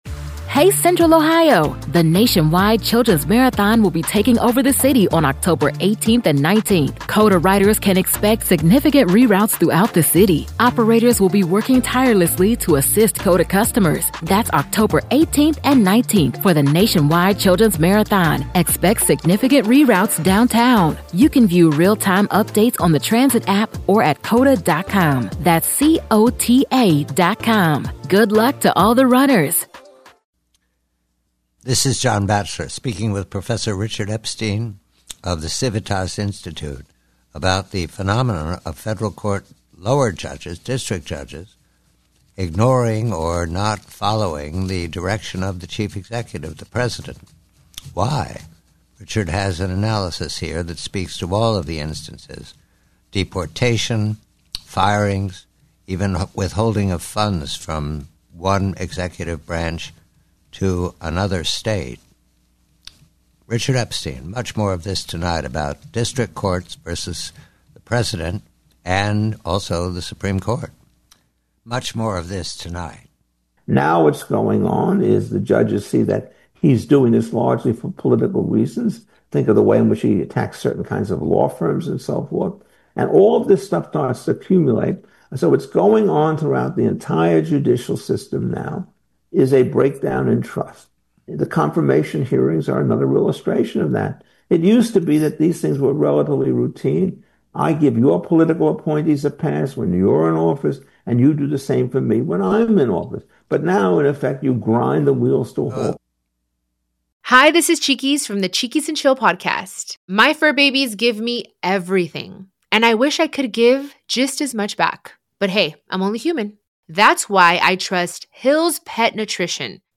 PREVIEW 2: A conversation with Professor Richard Epstein regarding federal court judges and their relationship with the chief executive and the Supreme Court.